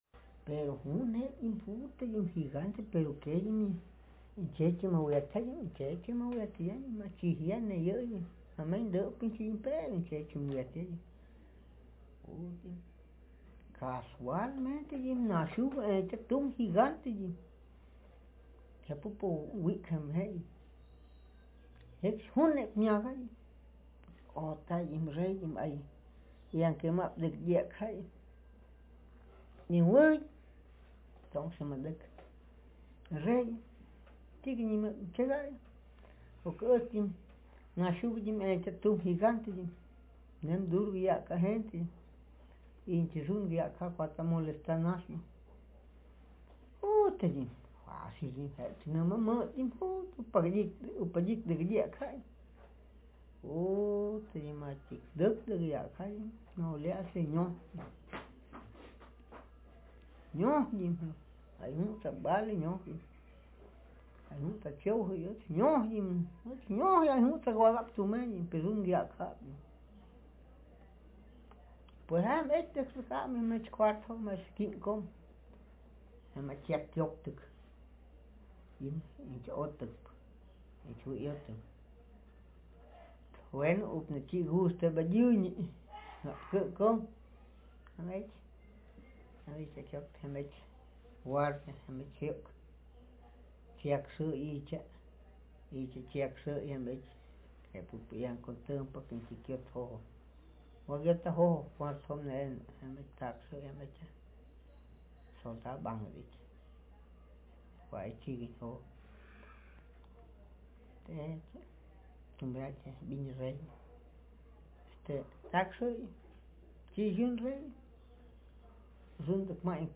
Speaker sex m Text genre traditional narrative